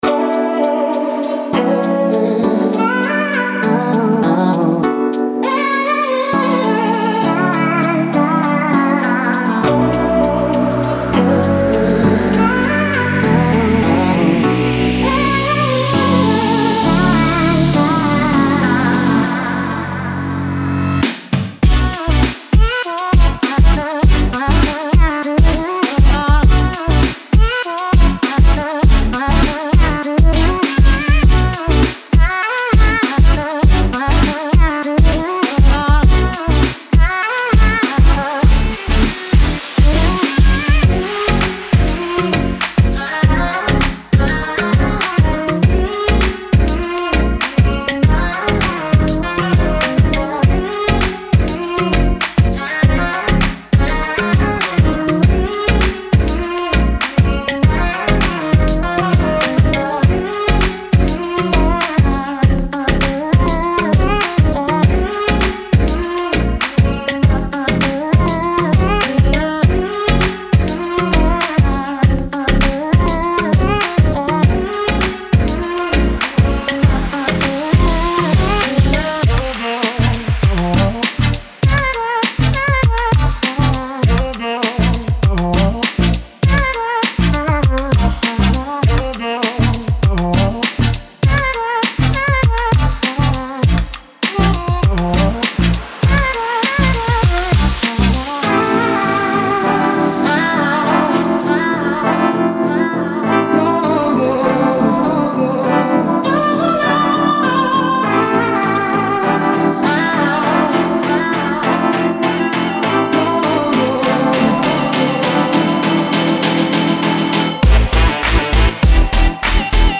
EDMFuture BassHouse
Progressive House Grooves